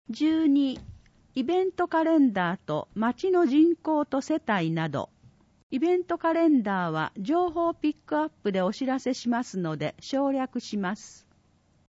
広報とうごう音訳版（2021年2月号）